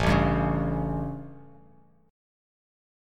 A7 Chord
Listen to A7 strummed